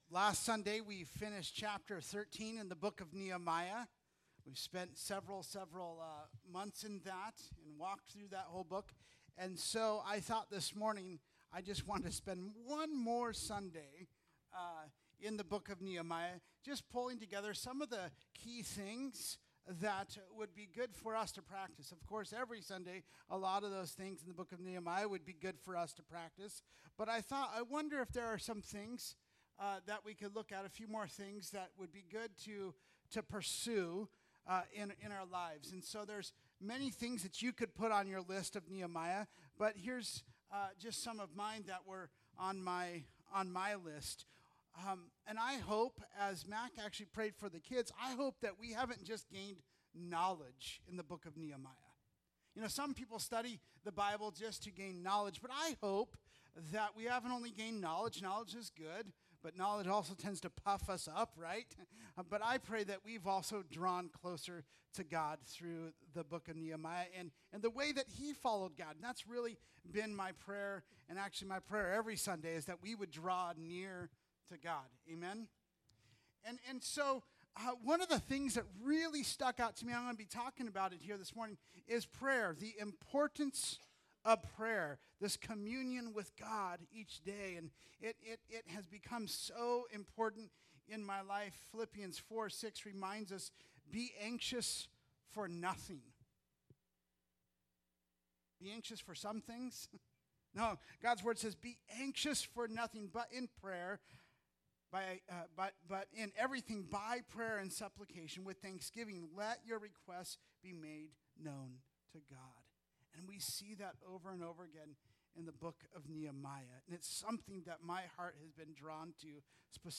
Audio Sermons | Tonasket Free Methodist Church